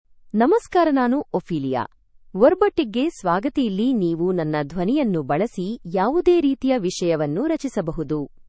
Ophelia — Female Kannada AI voice
Ophelia is a female AI voice for Kannada (India).
Voice sample
Listen to Ophelia's female Kannada voice.
Female
Ophelia delivers clear pronunciation with authentic India Kannada intonation, making your content sound professionally produced.